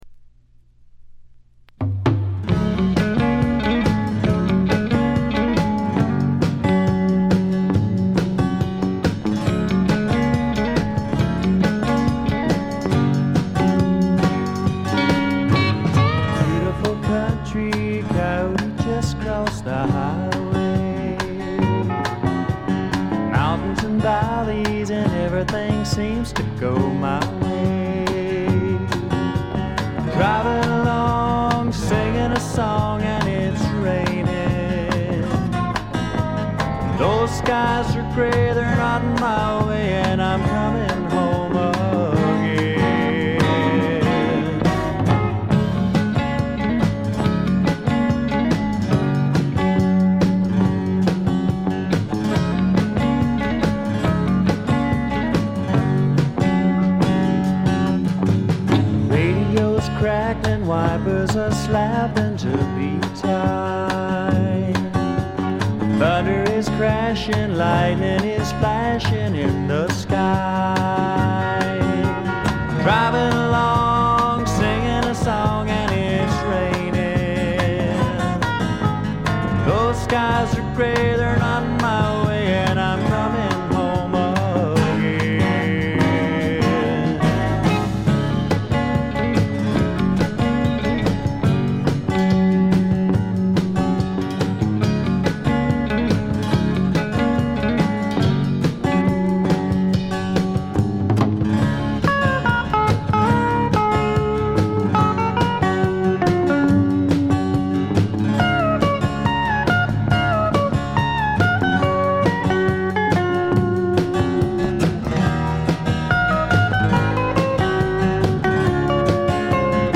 ほとんどノイズ感無し。
カントリー風味の曲とかファンキーな曲とかもありますが、クールでちょいメロウな曲調が特に素晴らしいと思います。
試聴曲は現品からの取り込み音源です。
Recorded at - Real To Reel , Garland, Texas